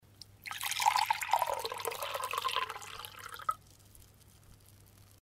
Звуки наливания воды, жидкости
На этой странице собраны разнообразные звуки наливания воды и других жидкостей: от наполнения стакана до переливания напитков в высокий бокал.